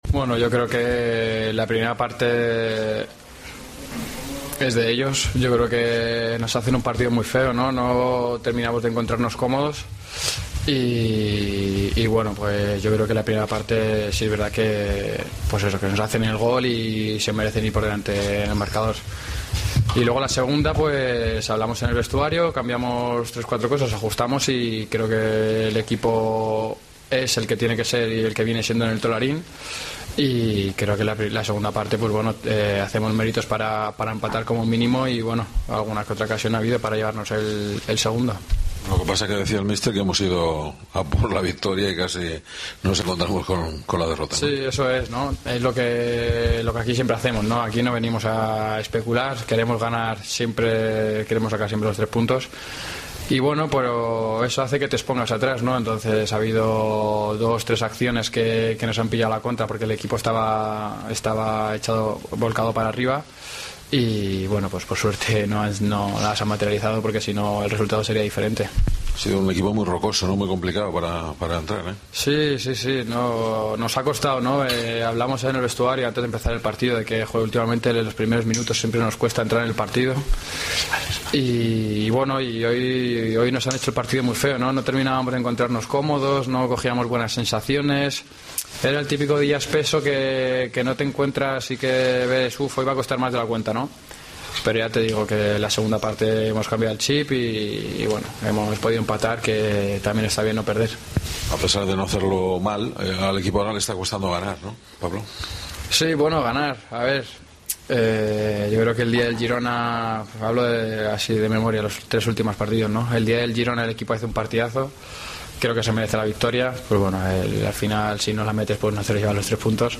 POSTPARTIDO
Escucha aquí las palabras de los dos jugadores blanquiazules